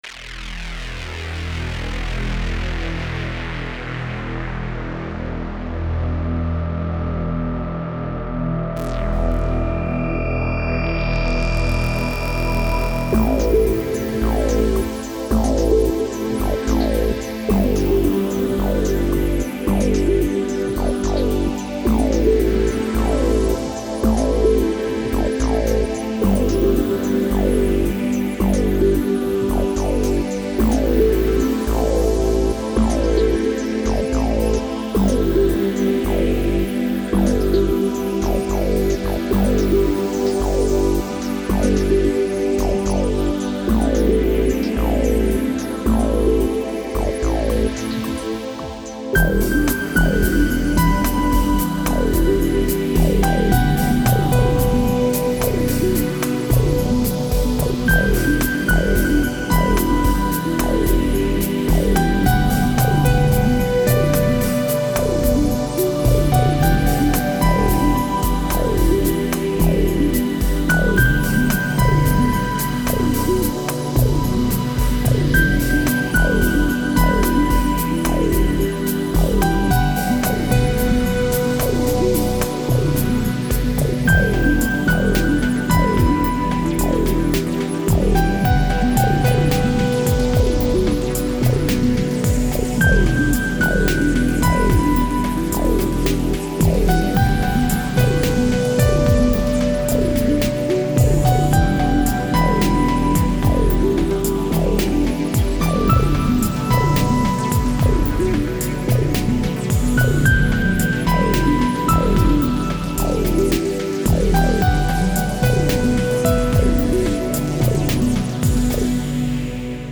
Electronic music inspired by space